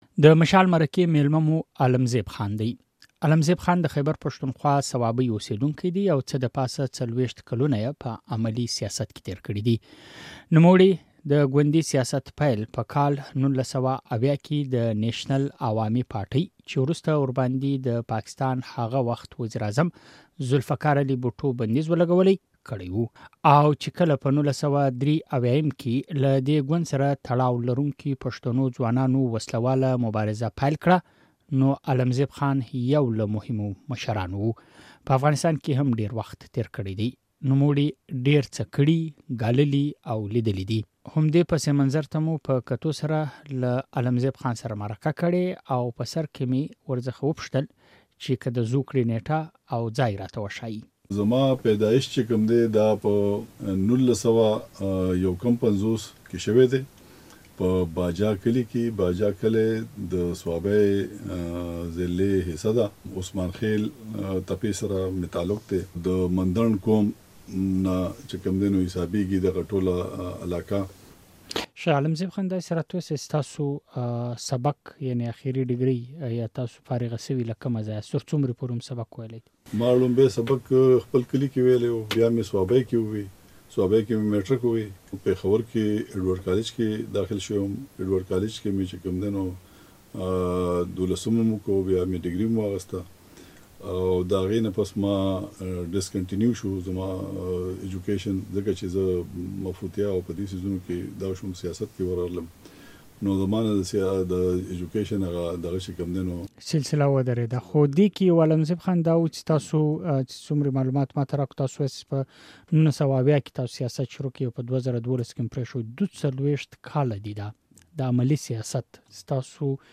نوموړی د مشال راډيو په اوونيزه خپرونه د مشال مرکه کې مېلمه و.